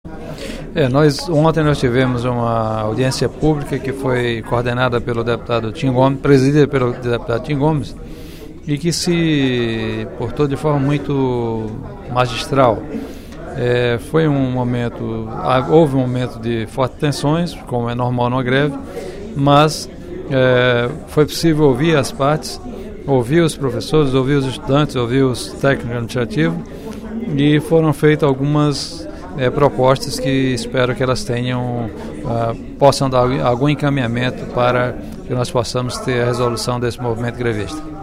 O deputado Professor Pinheiro (PT) informou, no primeiro expediente da sessão plenária desta quinta-feira (05/12), que participou ontem de audiência pública com o secretário de Ciência e Tecnologia, Renê Barreira, e com representantes dos professores universitários em greve, para tratar do movimento paredista nas três universidades estaduais.